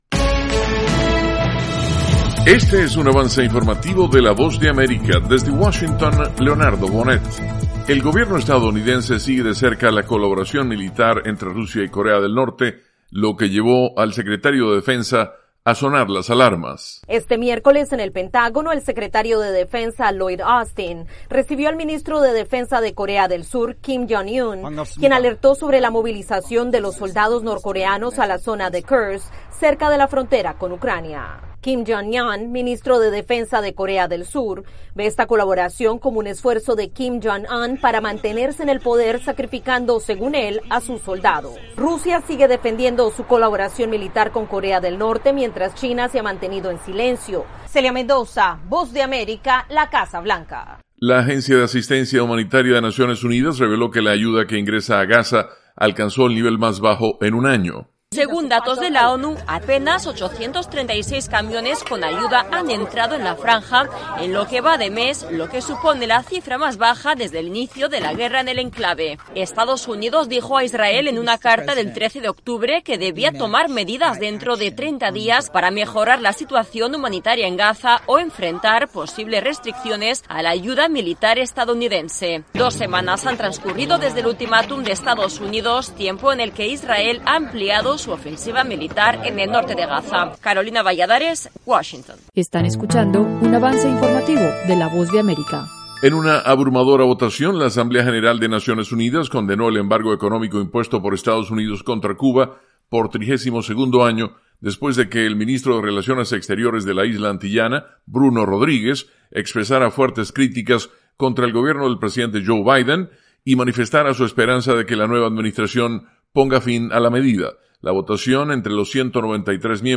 Avance Informativo
El siguiente es un avance informativo presentado por la Voz de América, desde Washington